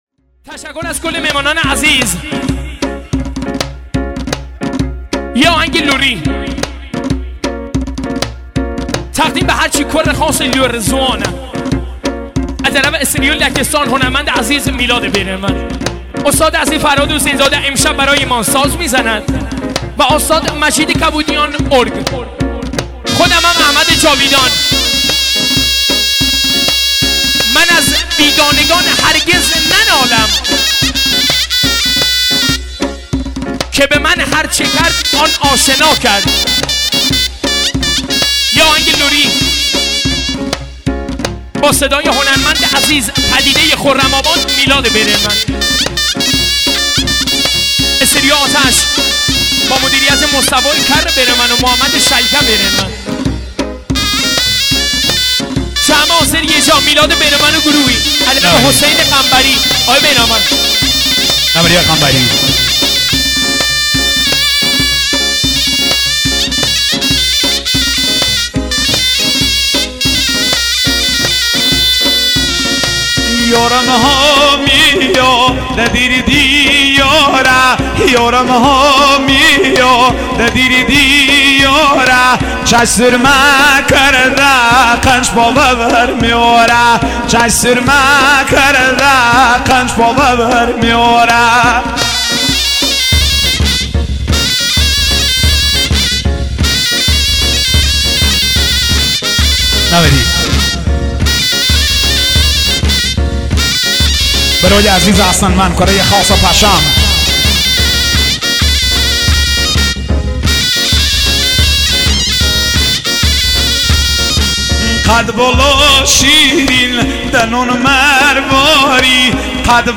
آهنگ کردی و سنندجی